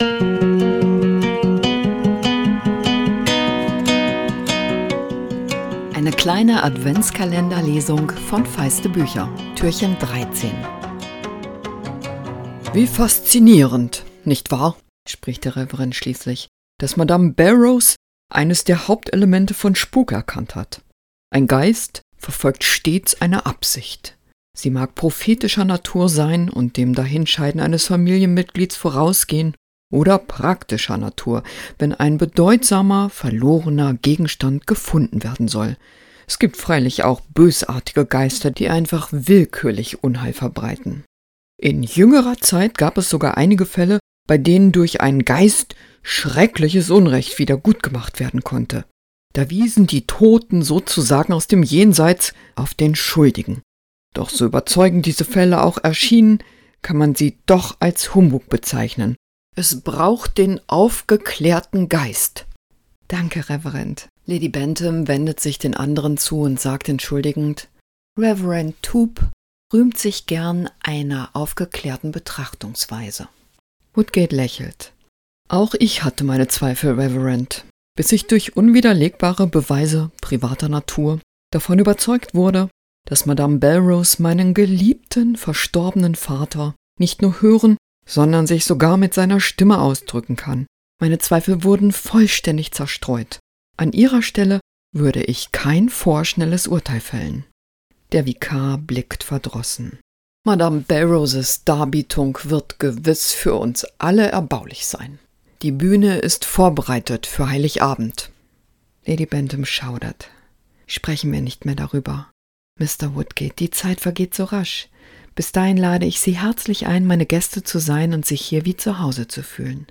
Adventskalender-Lesung 2024! Jess Kidd nimmt euch mit ins Leben des Waisenmädchens Ada Lark. Der Reverend, der auf die Séance wartet, glaubt zwar an den Heiligen Geist, aber sonst erweist er sich als Skeptiker...